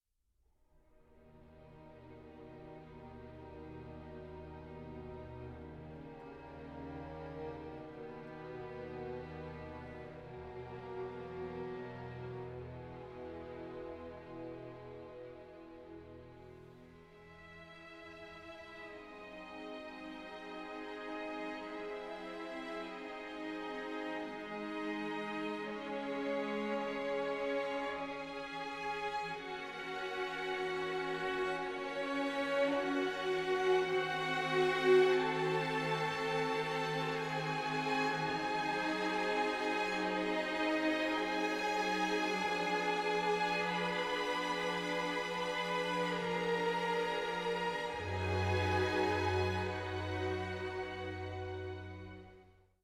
Complete Incidental Music
Recorded 3-7 February 2014 at Turku Concert Hall, Finland